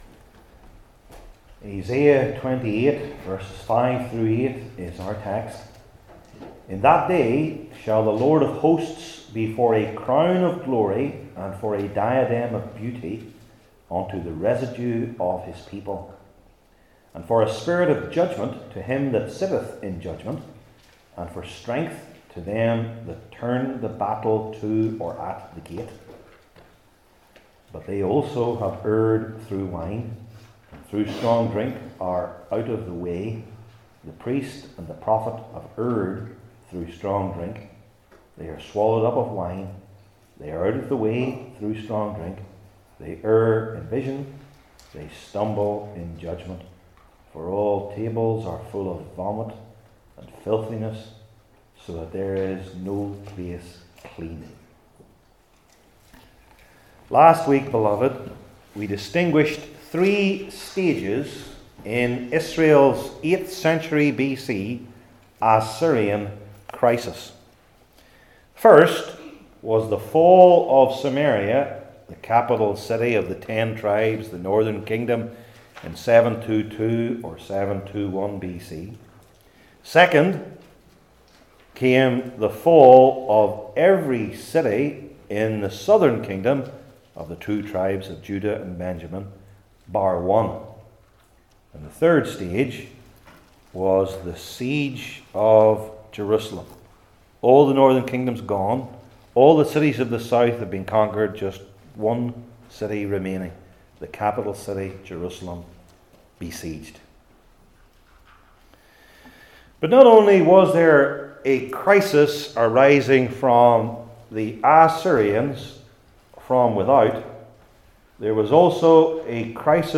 Old Testament Sermon Series I. Who?